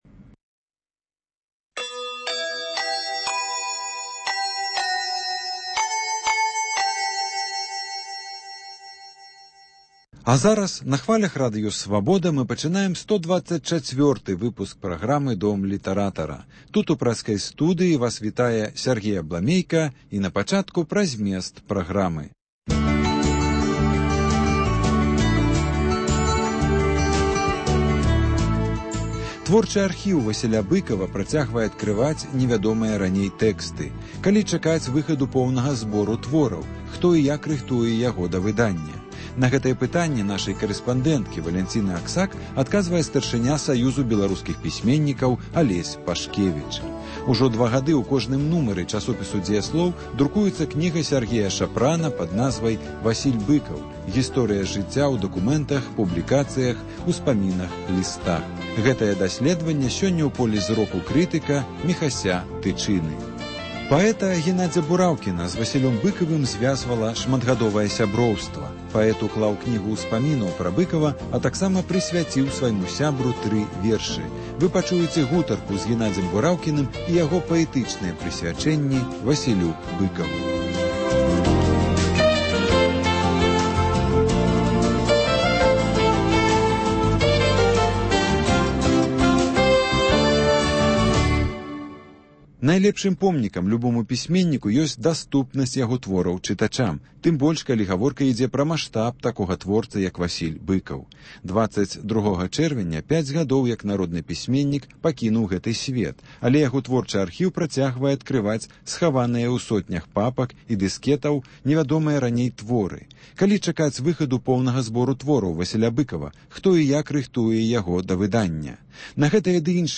Літаратурны агляд